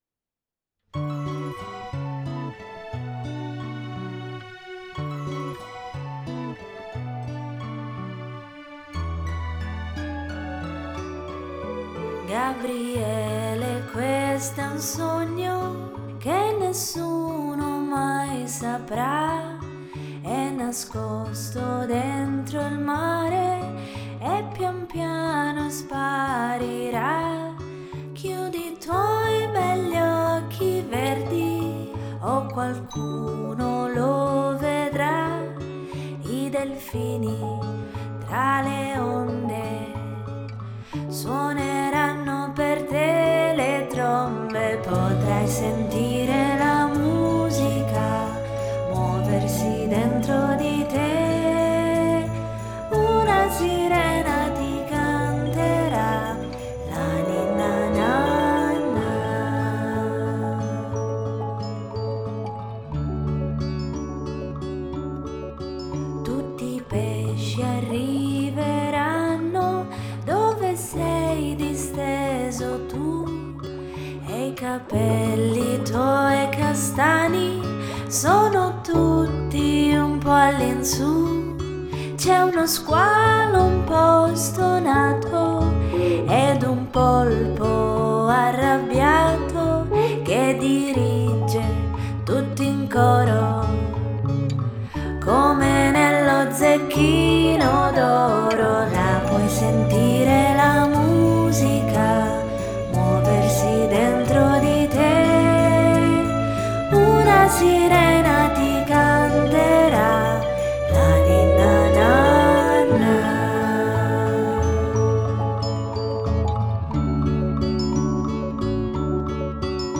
La nostra Ninna nanna per bambini
Una melodia dolce e delicata suonata per i vostri piccoli.